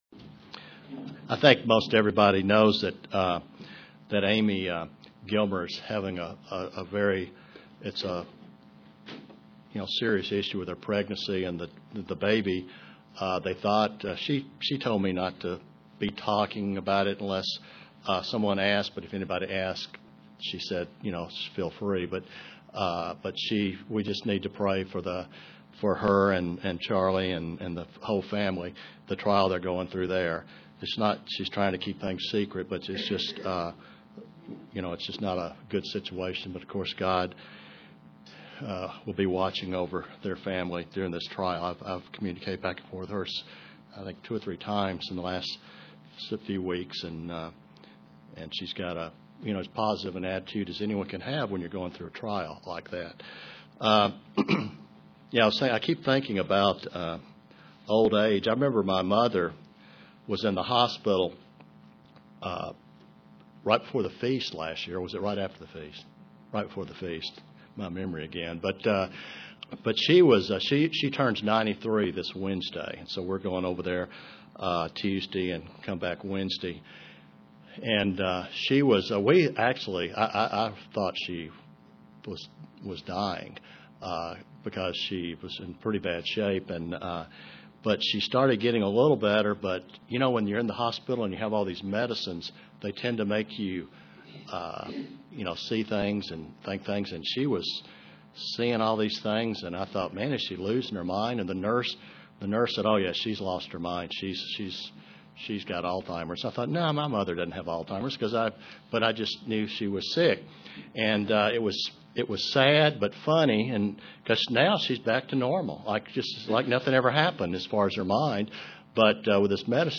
Print Five points to consider as we grow toward being reconciled to God UCG Sermon Studying the bible?